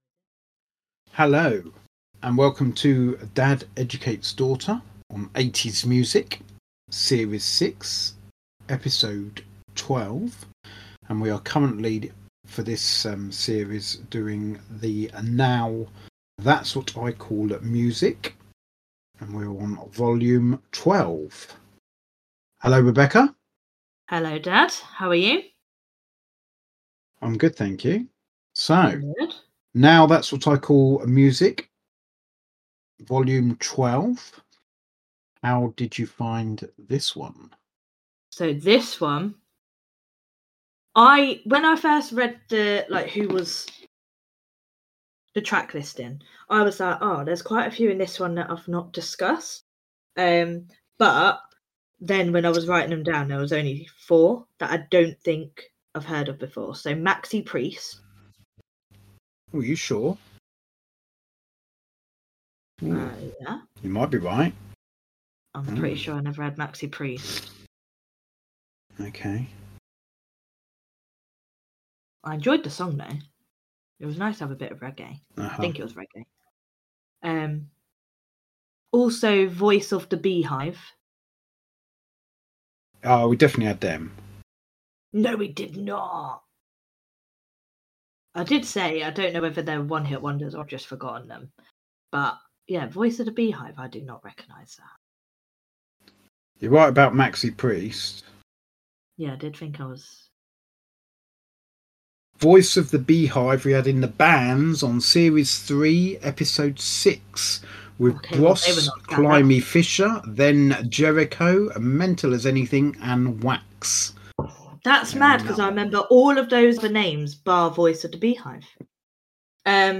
some technical problems were happening that we were unaware of during recording.